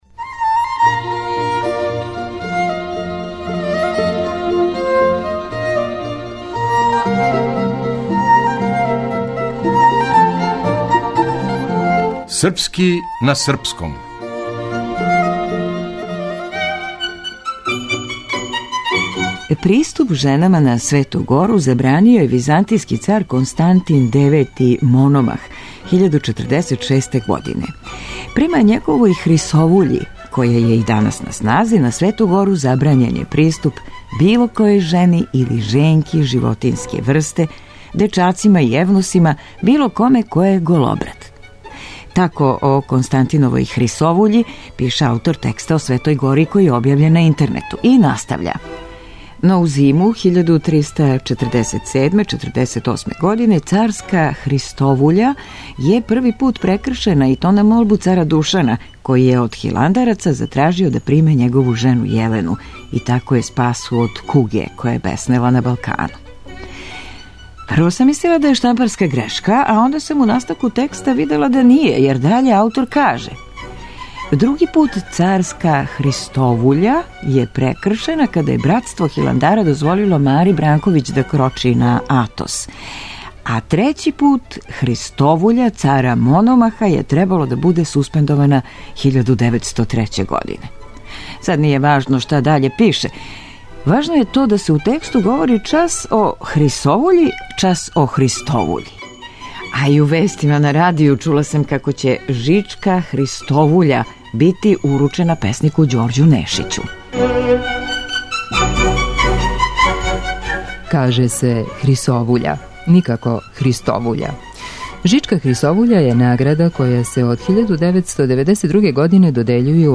Драмска уметница